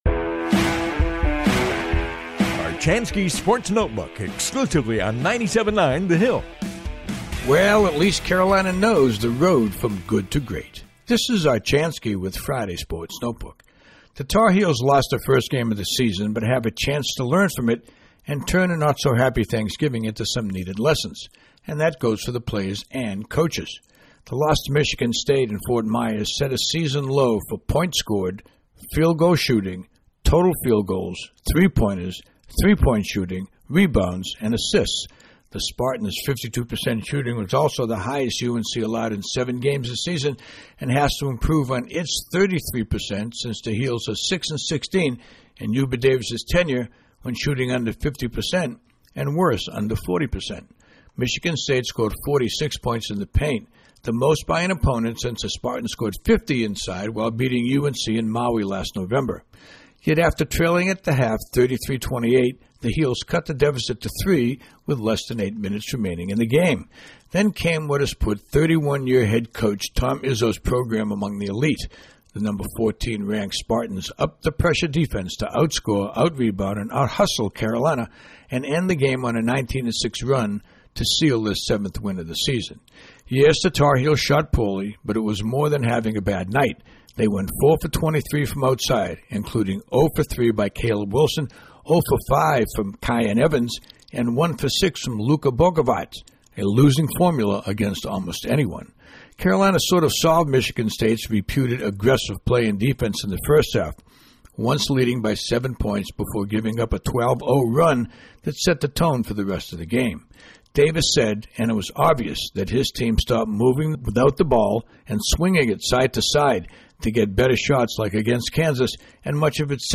commentary airs daily on the 97.9 The Hill WCHL